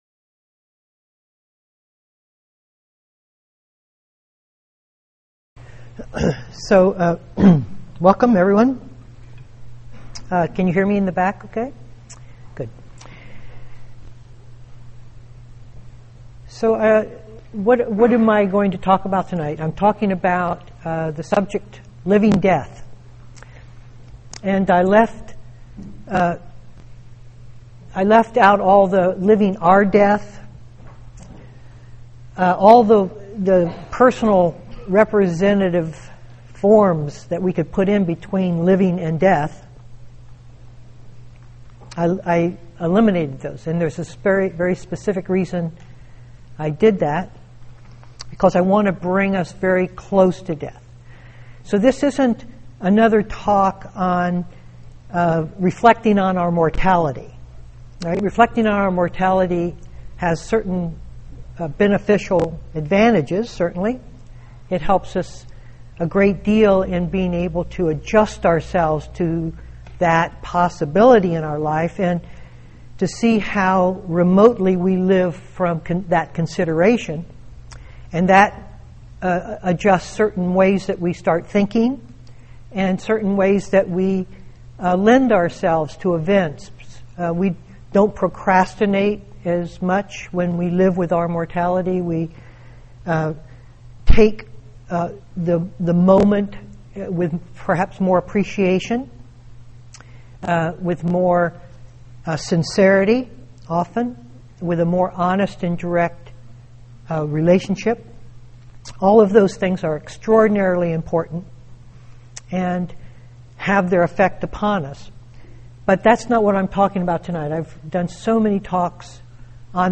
2014-01-21 Venue: Seattle Insight Meditation Center